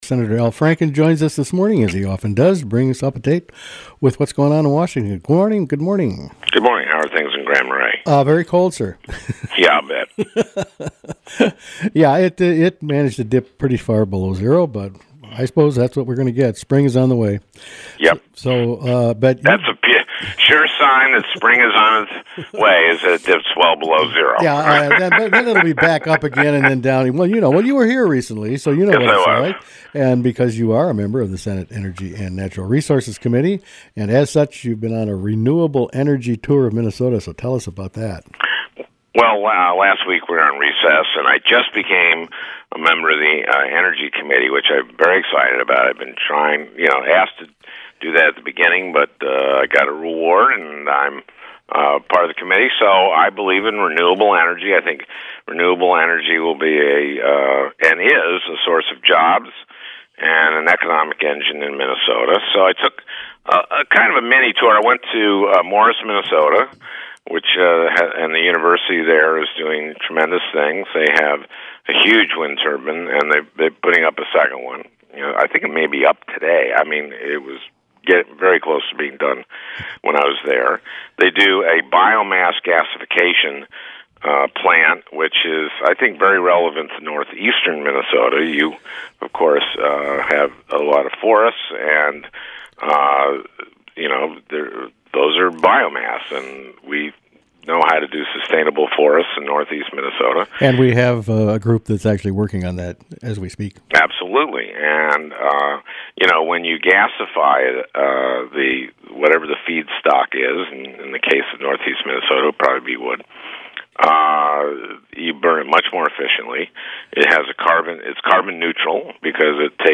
Sen. Al Franken interviewed on "Daybreak"